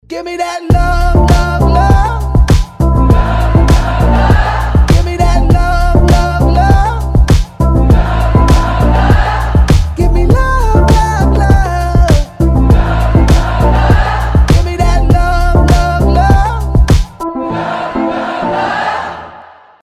• Качество: 320, Stereo
dancehall